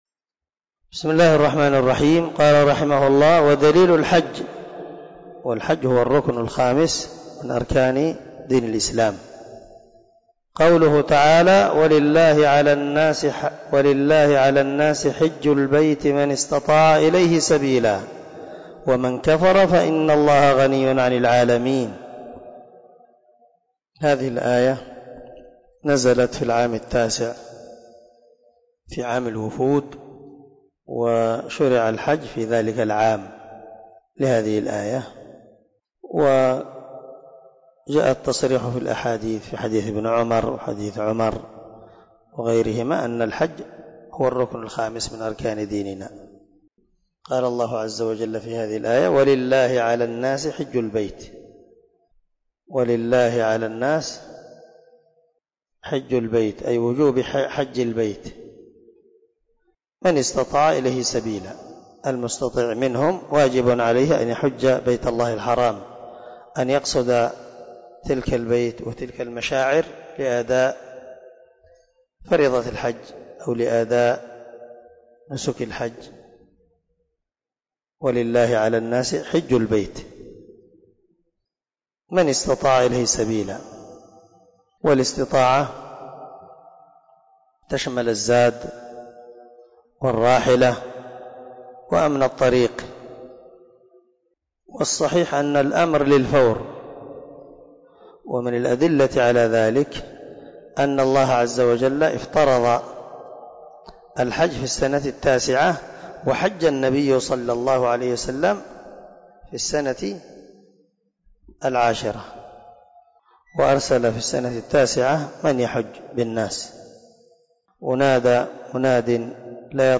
🔊 الدرس 27 من شرح الأصول الثلاثة